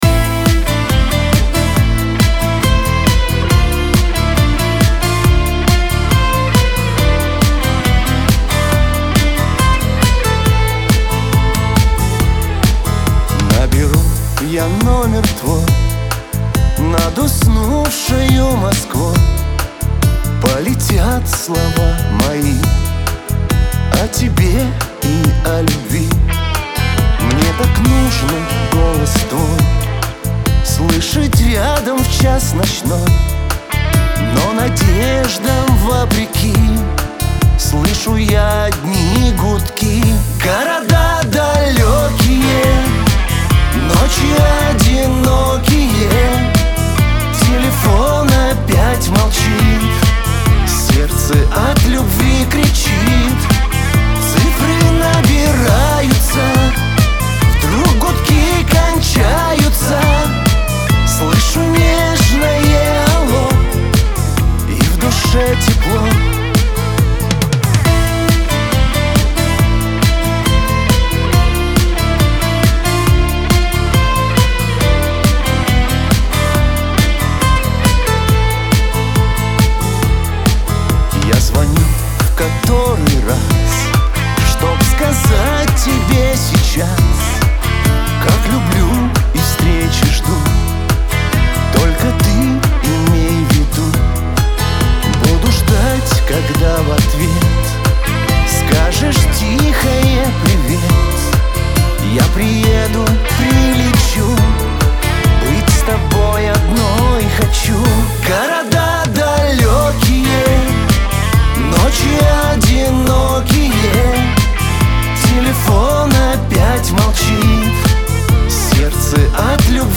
диско
dance , pop
эстрада